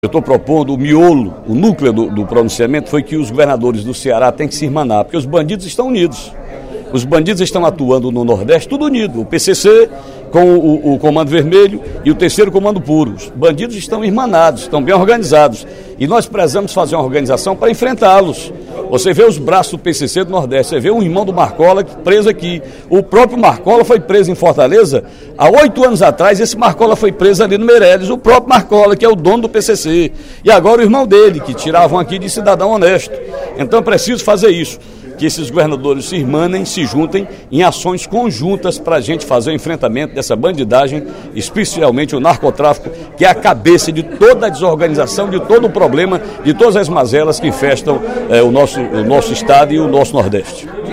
O deputado Ferreira Aragão (PDT) sugeriu, em pronunciamento no primeiro expediente da sessão plenária da Assembleia Legislativa desta quinta-feira (31/03), medidas para minimizar o problema da violência no Nordeste. Segundo o parlamentar, é preciso criar o Pacto Interestadual do Narcotráfico.